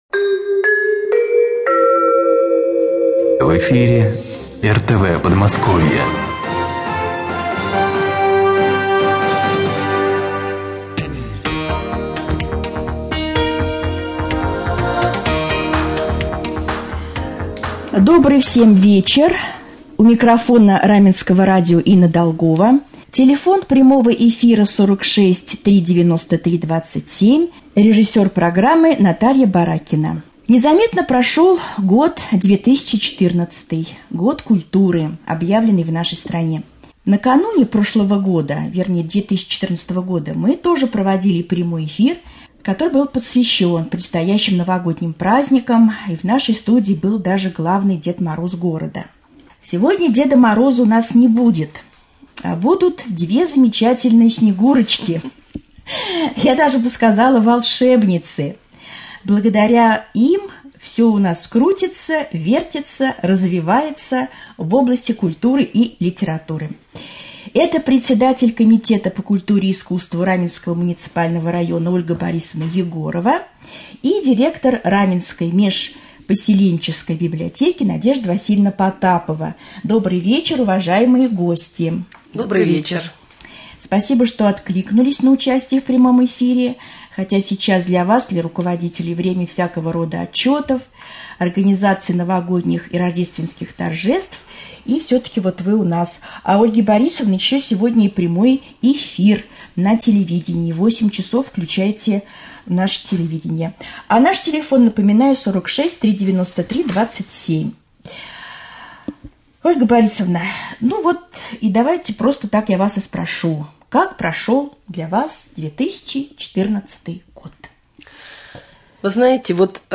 Прямой-эфир.mp3